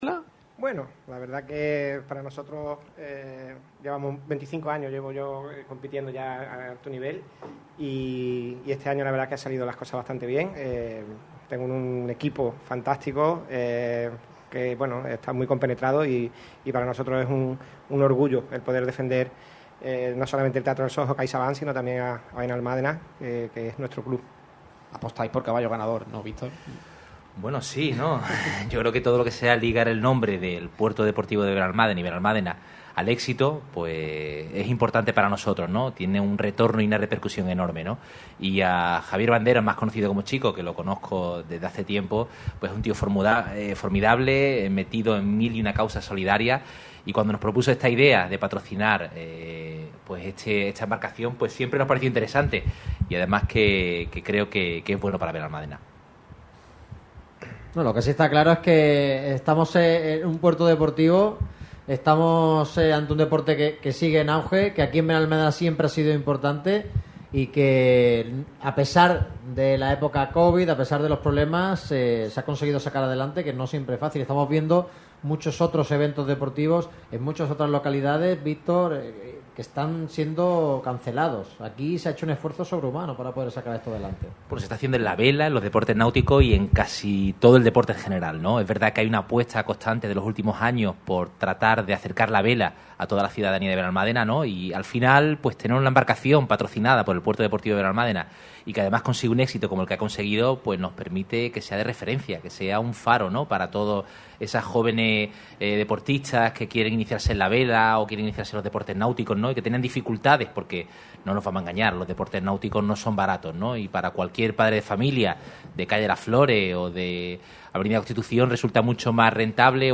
Radio MARCA Málaga se ha ‘subido al barco’ de la Capitanía del Puerto Deportivo de Benalmádena.
Durante la estancia, muchos han sido los protagonistas que han pasado por los micrófonos.